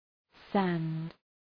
Προφορά
{sænd}